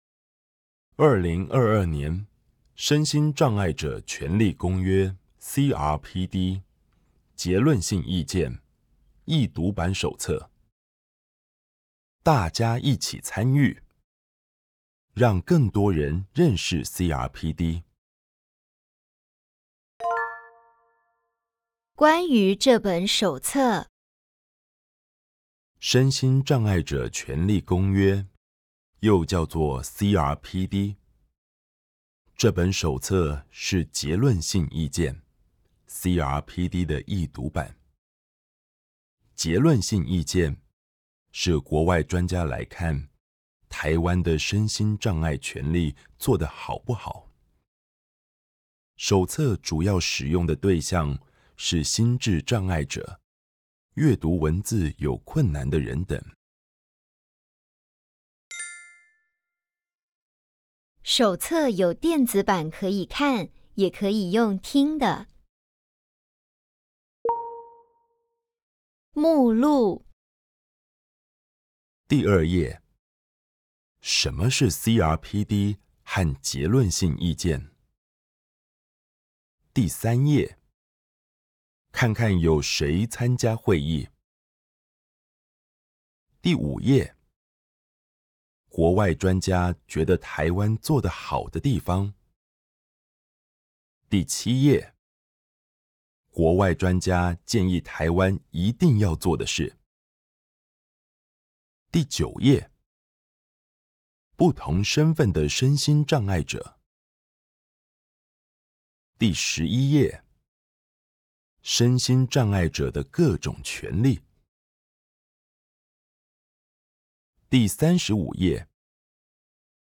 二、衛生福利部社會及家庭署為保障身心障礙者資訊近用權， 並推廣易讀格式，該署將111年8月8日發布之CRPD第二次國 家報告國際審查結論性意見轉譯為旨揭易讀版手冊，並錄製易讀版有聲書。